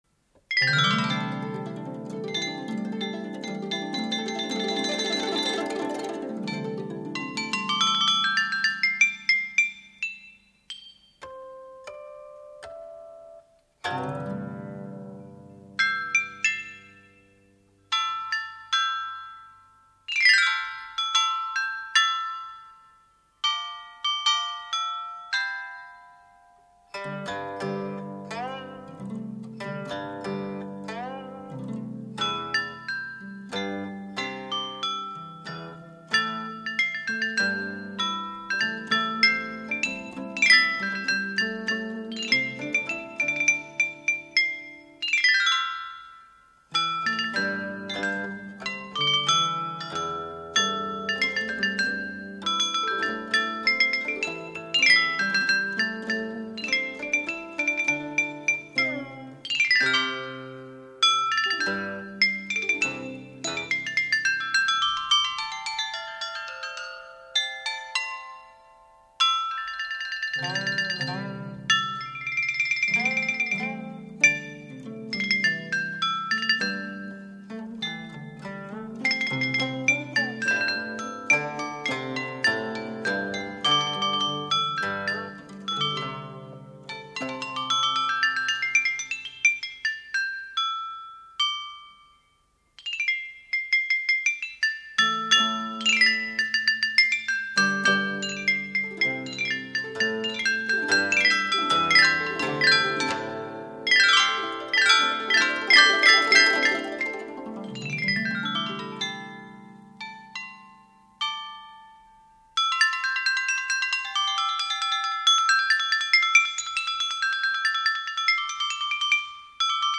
音乐类型：民乐
为再现历史真实风貌与先秦钟乐的音乐风格，本专辑部分曲目用的是已达数千年历史的出土乐器演奏。
Bianqing
编磬